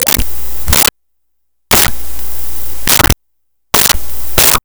Water Drops Single
Water Drops Single.wav